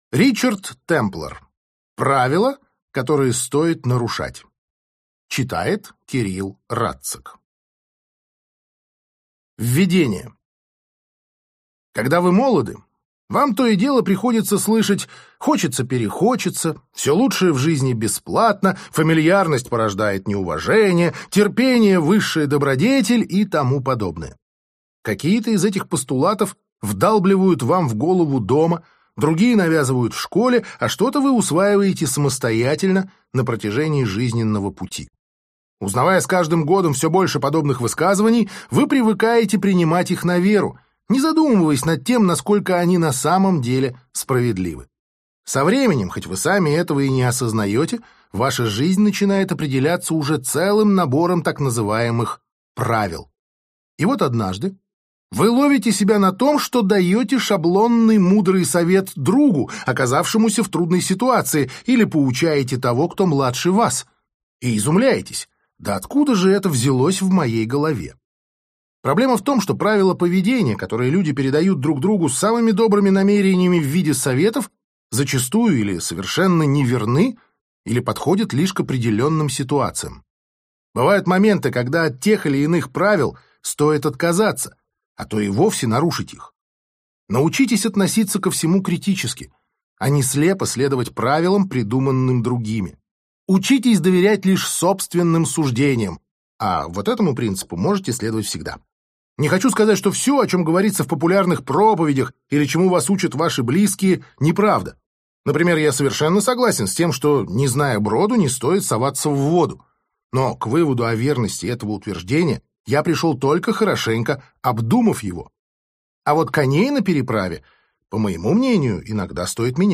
Аудиокнига Правила, которые стоит нарушать | Библиотека аудиокниг